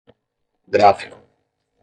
Pronunciato come (IPA)
/ˈɡɾa.fi.ku/